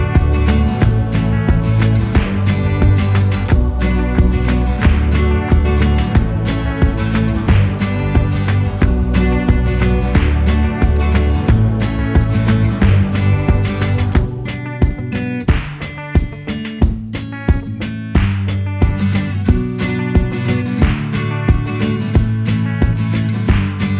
Minus Main Guitar Pop (2010s) 3:19 Buy £1.50